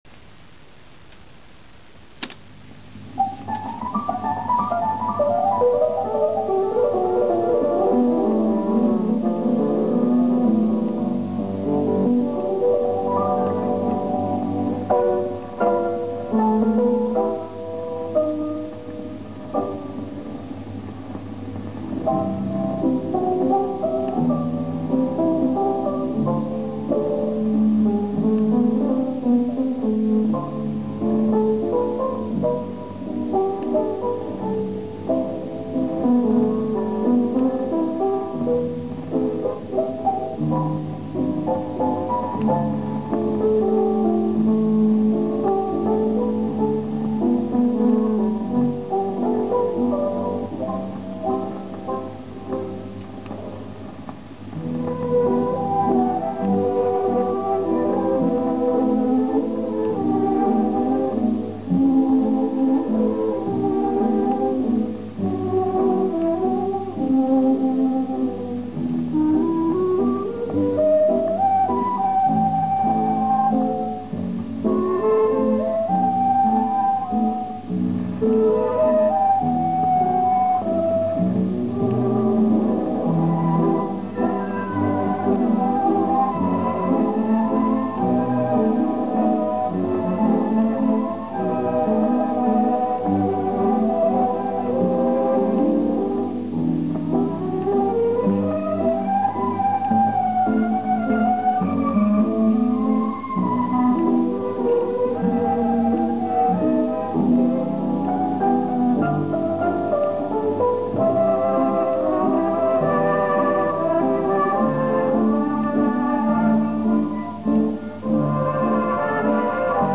Есть один старинный вальс... Не подскажите ли ..?
Вальс.mp3